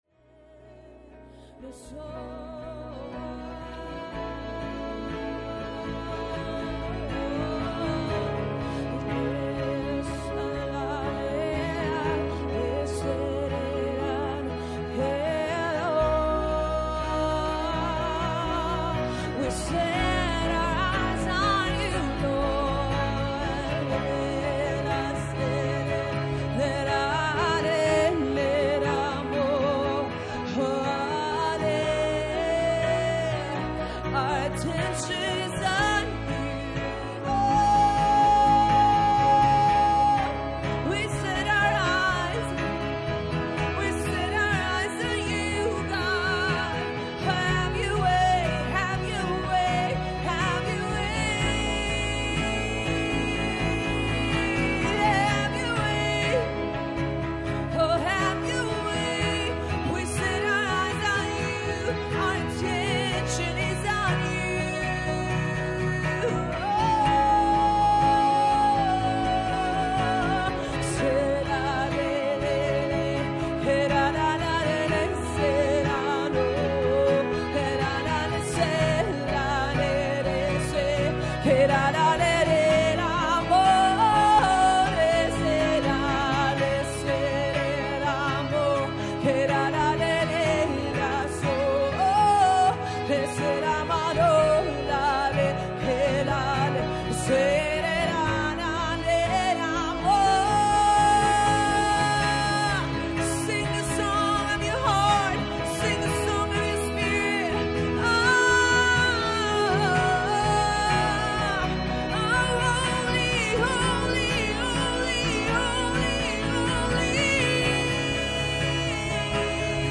TC Band Live Worship (December 9, 2018) - 10 Декабря 2018 - Главная Страница - Transformation Center
TC Band Live Worship (December 9, 2018).mp3